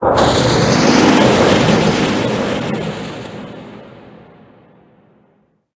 Cri de Salarsen Gigamax dans Pokémon HOME.
Cri_0849_Gigamax_HOME.ogg